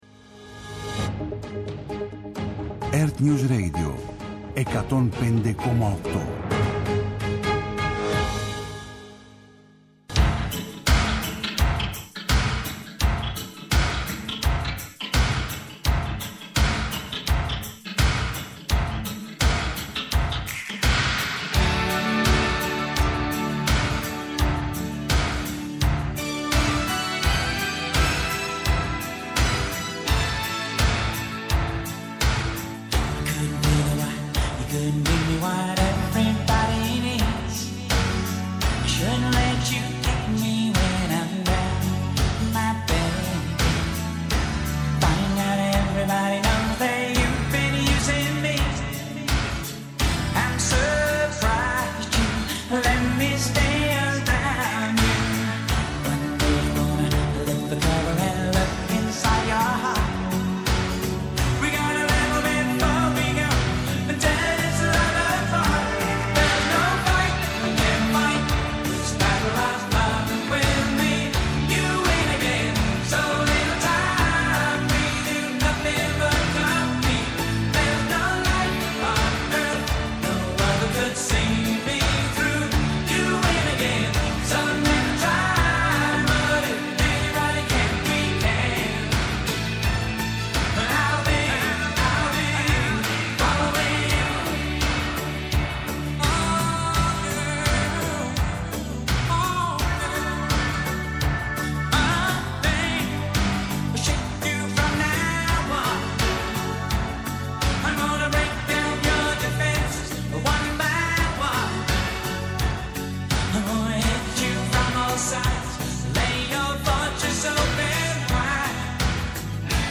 Νυχτερινές ιστορίες με μουσικές και τραγούδια που έγραψαν ιστορία.